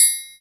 VEC3 Percussion 087.wav